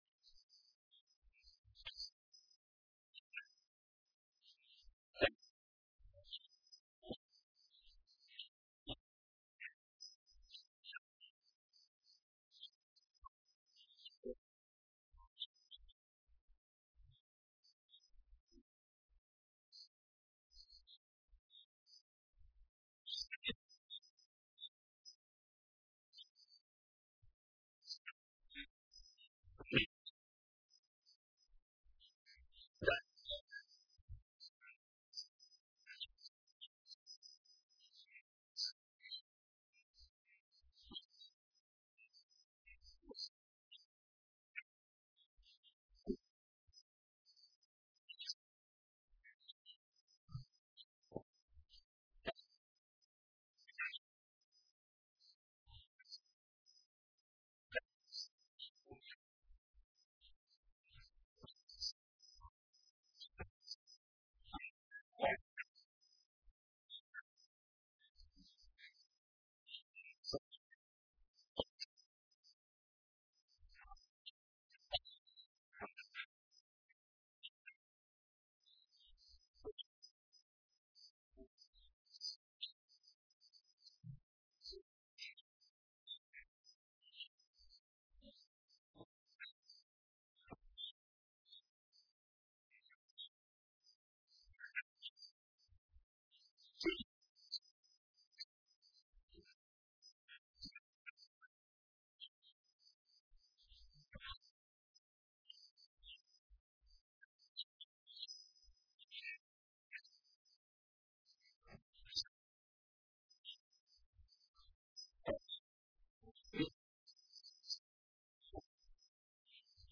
Online Sermons at St. Pauls